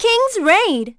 Juno-Vox_Kingsraid.wav